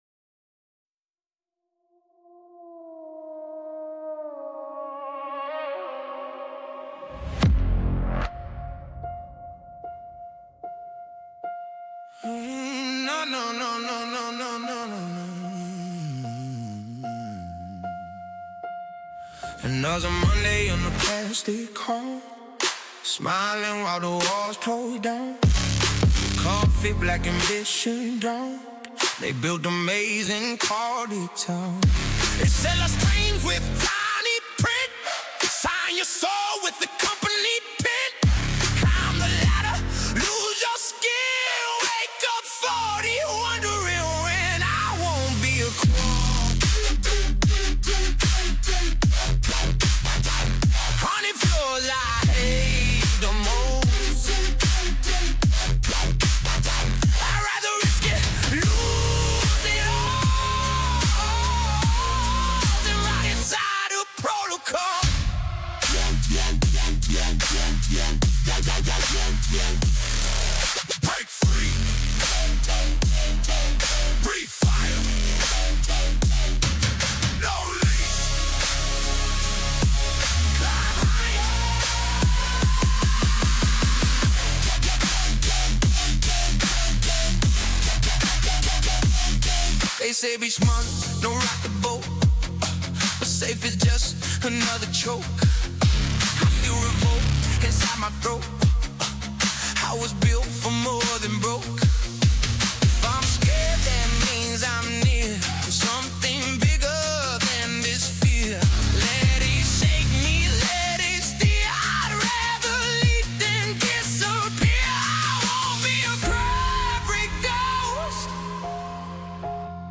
Official Studio Recording
a fusion of 140 bpm cinematic soul and gritty outlaw country